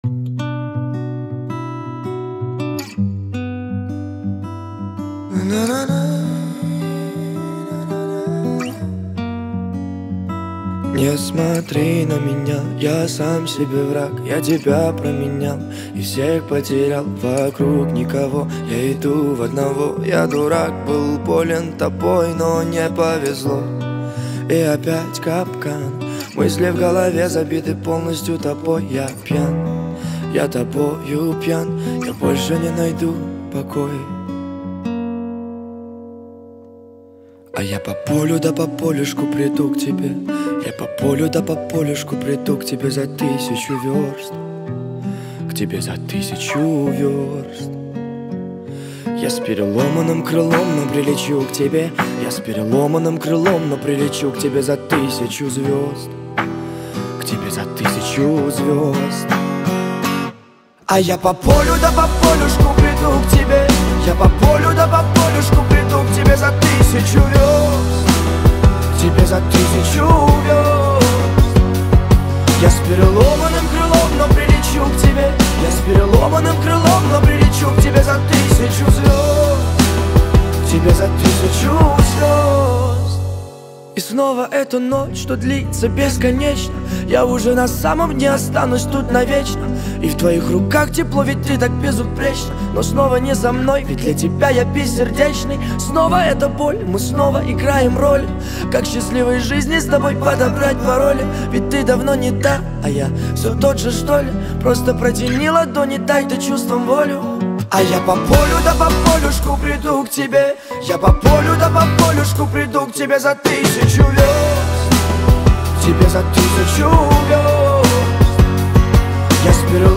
пронизана теплотой и нежностью к простым радостям жизни